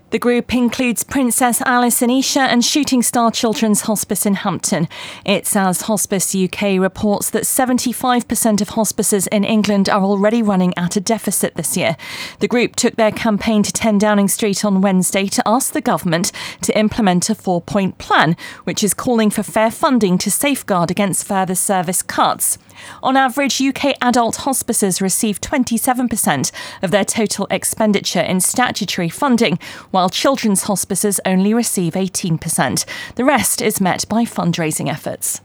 full report